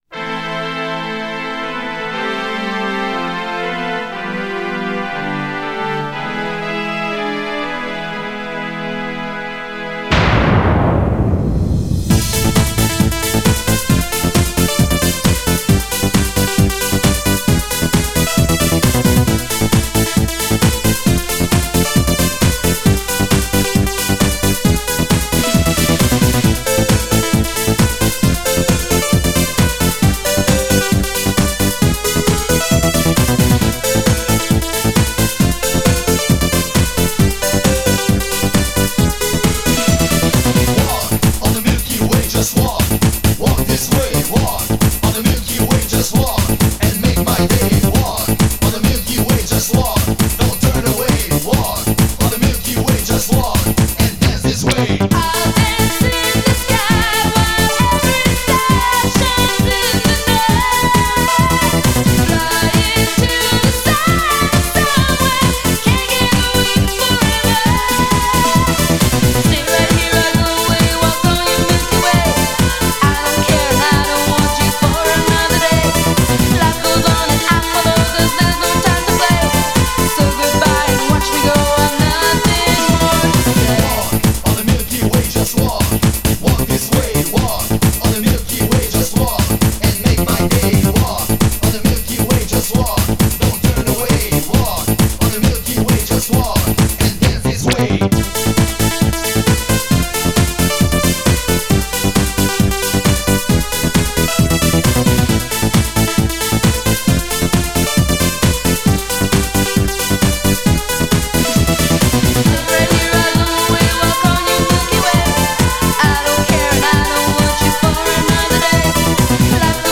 Genre: Eurodance.